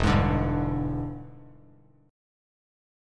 E7#9 chord